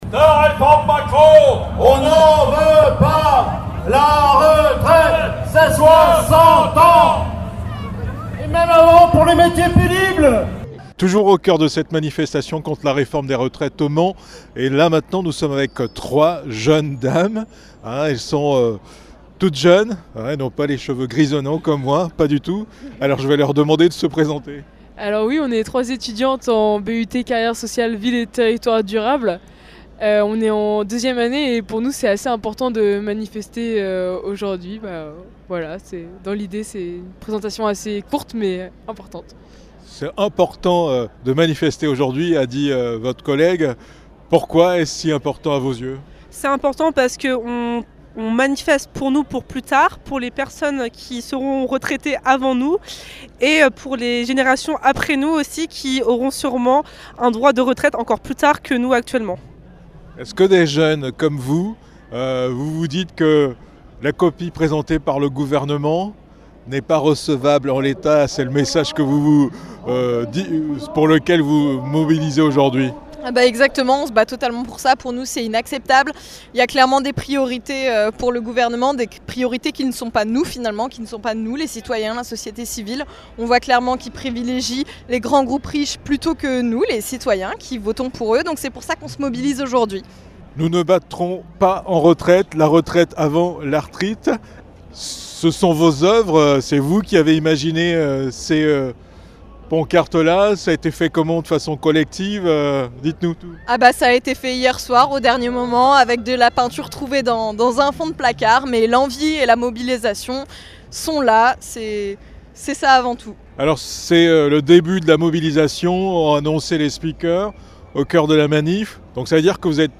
Manifestation contre la réforme des retraites au Mans
Manifestation contre la réforme des retraites - Etudiantes et étudiant